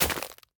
Minecraft Version Minecraft Version 25w18a Latest Release | Latest Snapshot 25w18a / assets / minecraft / sounds / block / nether_sprouts / break2.ogg Compare With Compare With Latest Release | Latest Snapshot